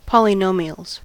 polynomials: Wikimedia Commons US English Pronunciations
En-us-polynomials.WAV